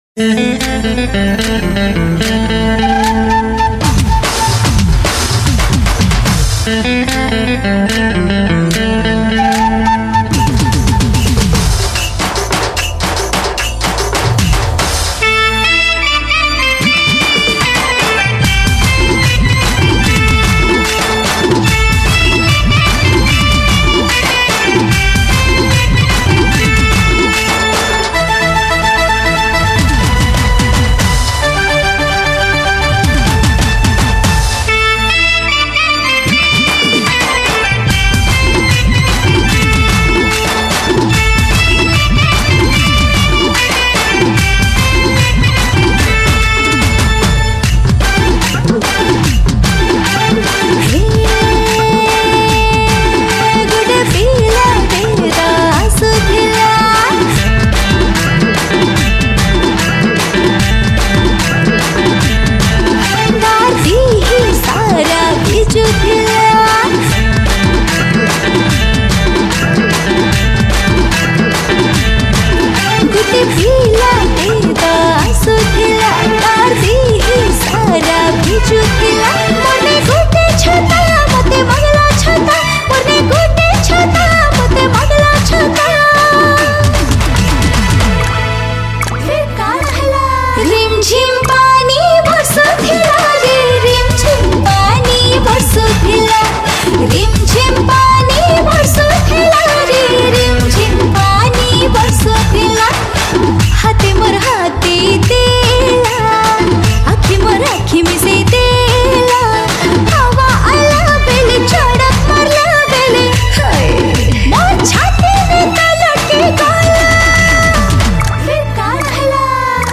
(Female) Sambalpuri Song